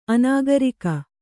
♪ anāgarika